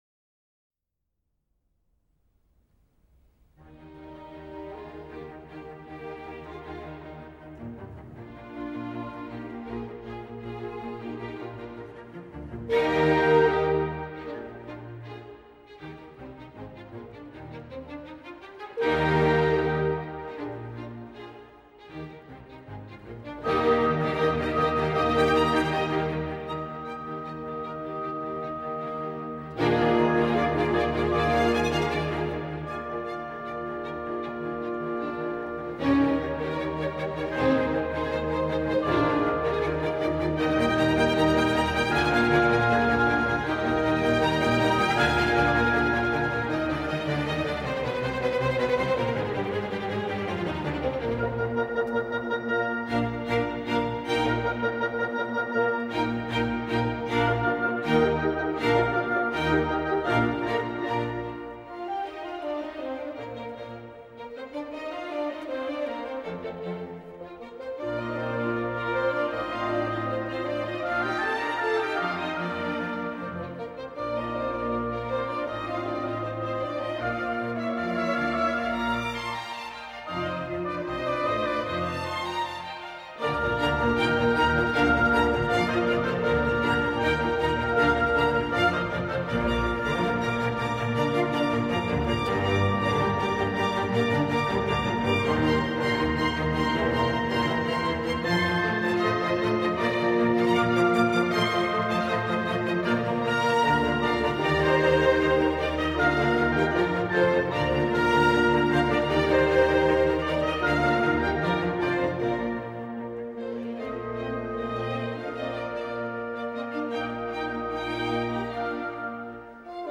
Symphony in E flat major, Op. 6, No. 2